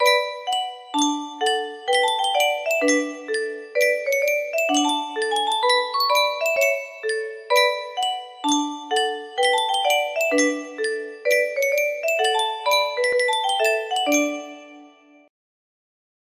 Yunsheng Music Box - Toyland Y046 music box melody
Full range 60